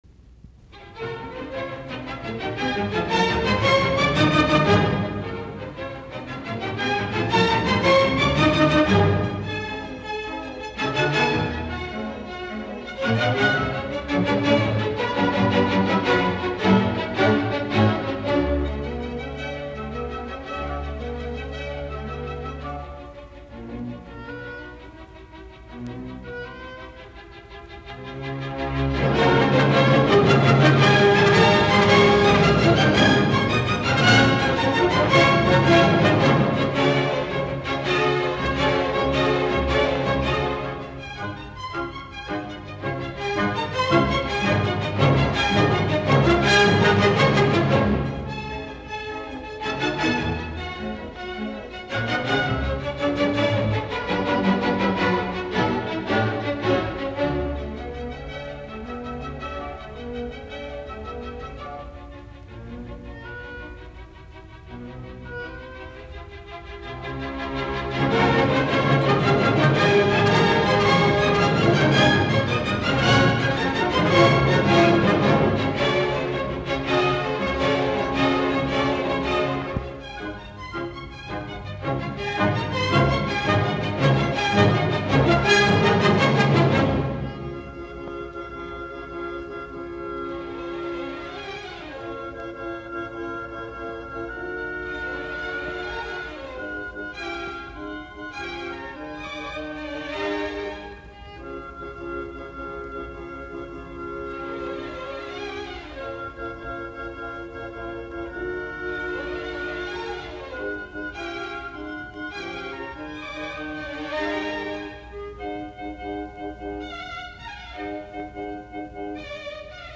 r1952, Carnegie Hall, New York.